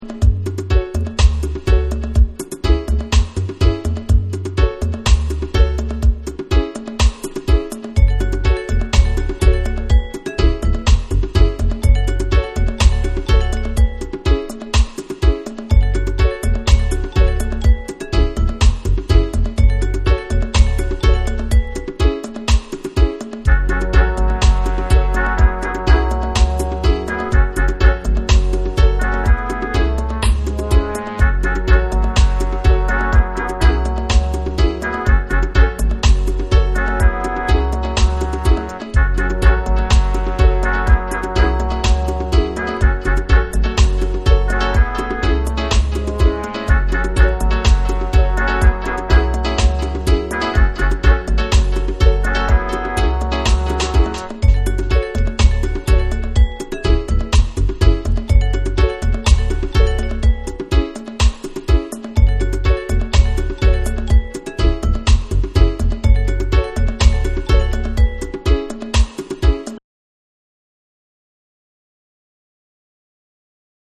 ヘビー・ウエイトなダブサウンドが楽しめる作品。
REGGAE & DUB